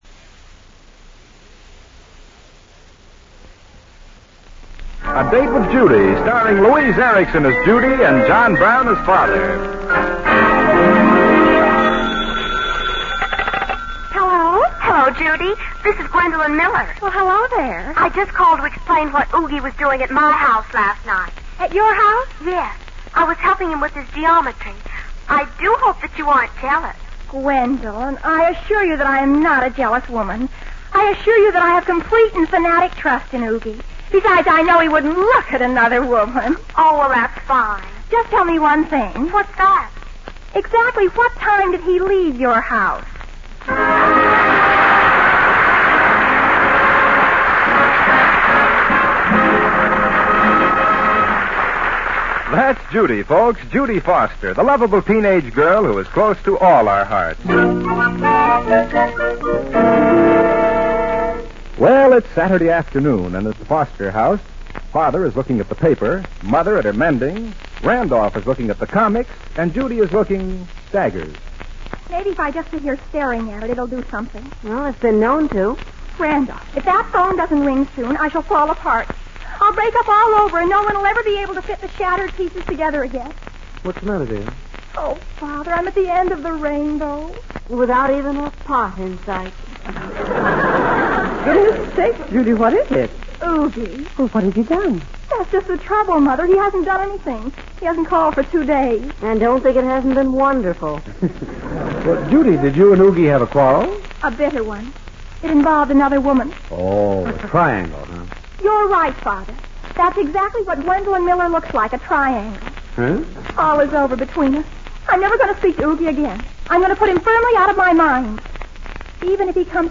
A Date with Judy Radio Program